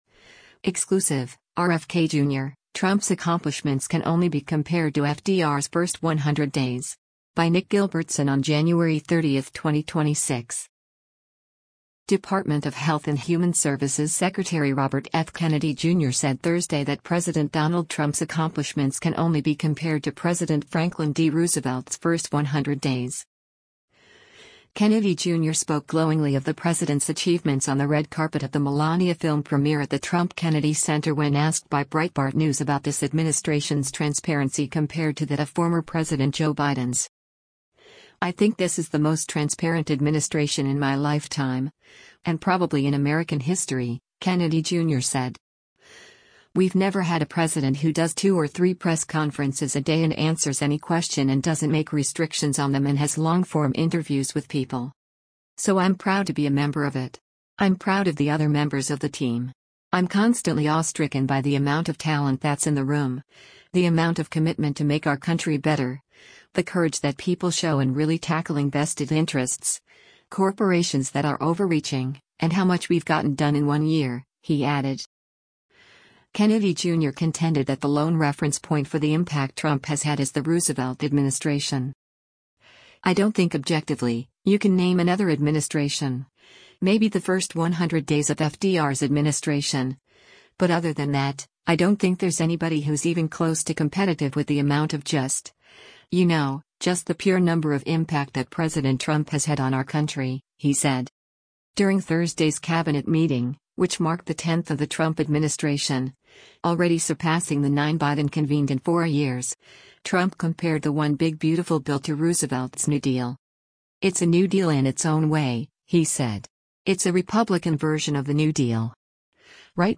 Kennedy Jr. spoke glowingly of the president’s achievements on the red carpet of the ‘Melania’ film premiere at the Trump-Kennedy Center when asked by Breitbart News about this administration’s transparency compared to that of former President Joe Biden’s.